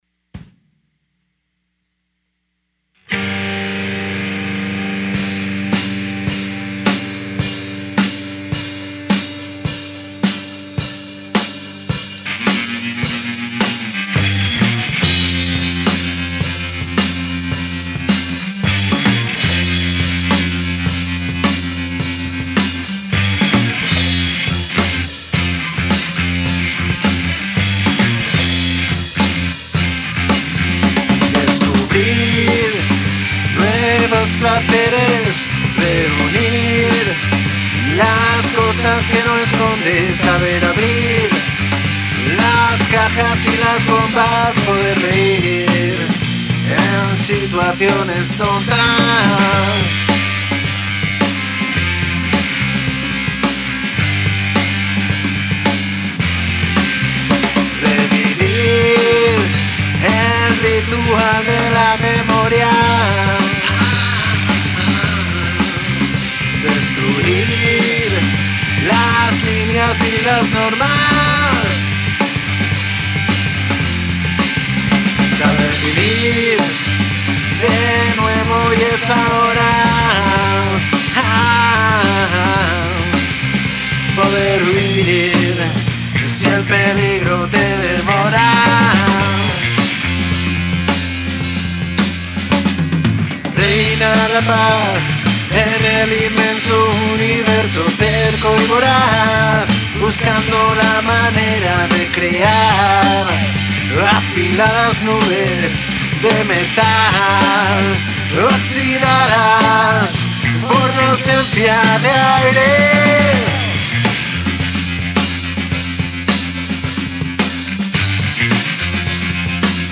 Genero: Pop/Rock